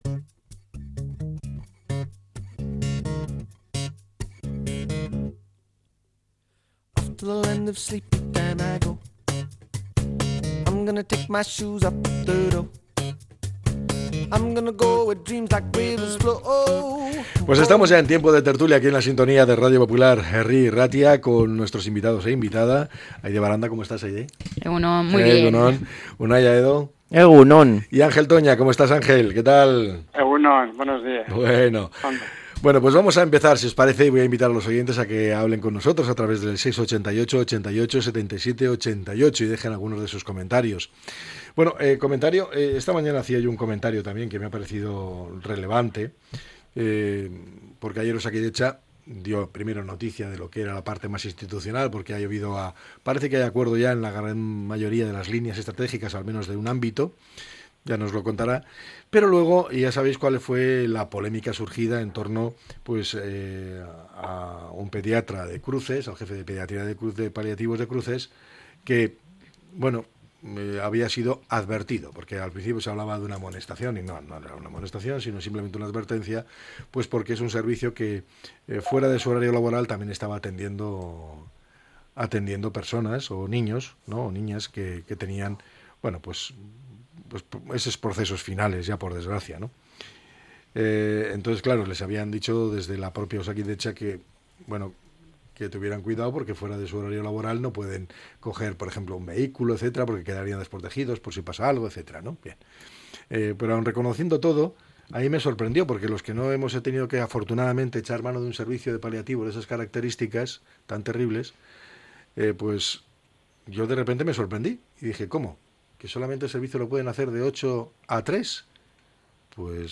La tertulia 04-06-25.